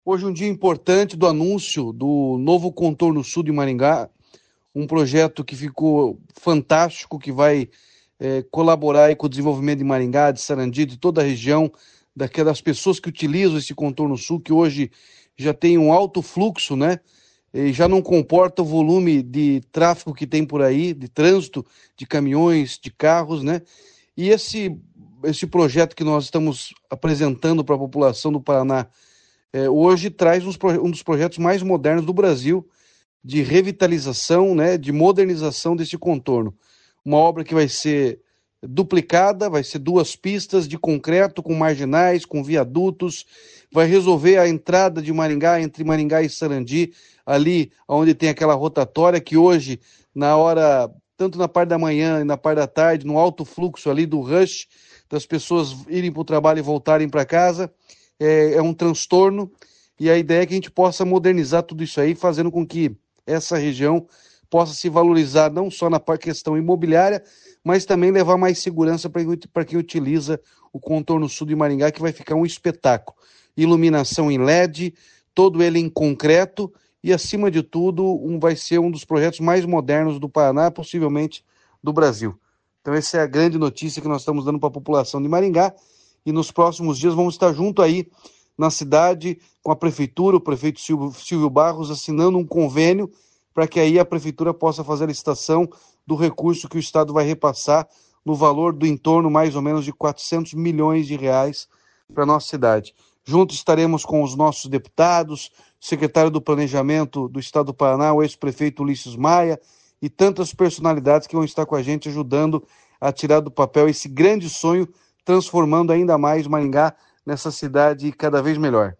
Sonora do governador Ratinho Junior sobre a duplicação do Contorno Sul de Maringá